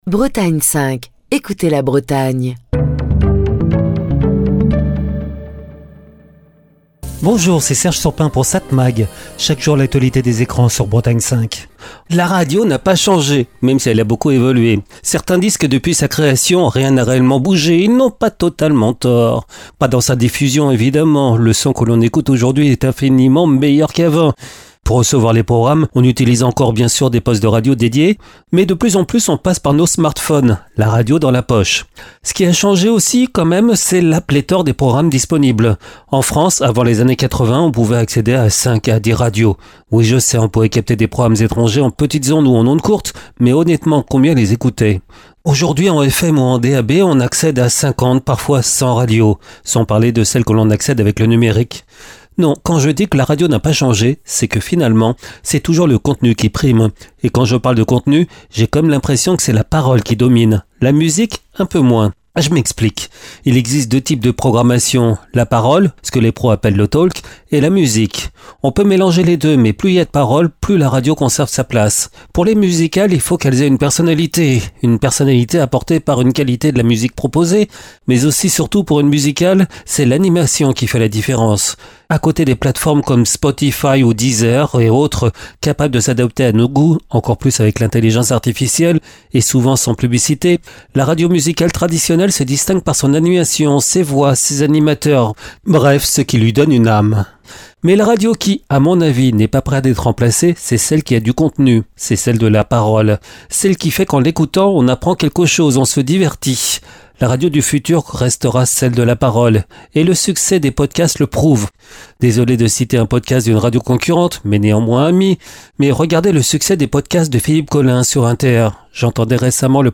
Chronique du 6 novembre 2025.